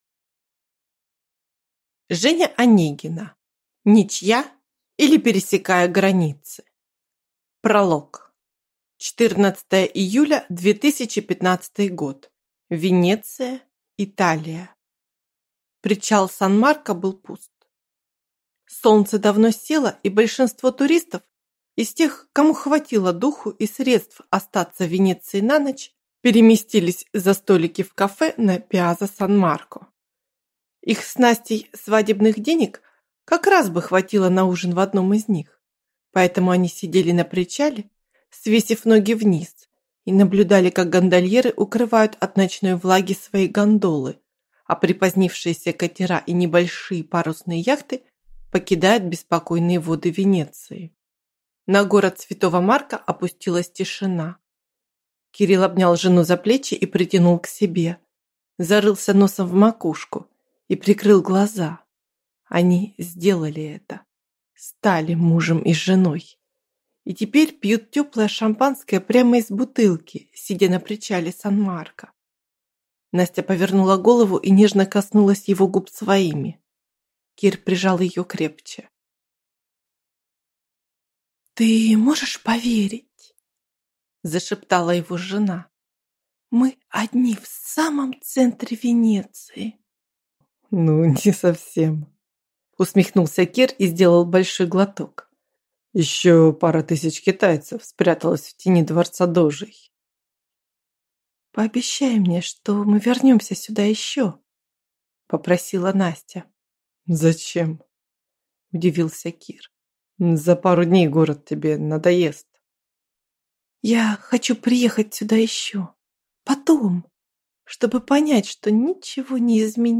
Аудиокнига Ничья, или пересекая границы | Библиотека аудиокниг
Прослушать и бесплатно скачать фрагмент аудиокниги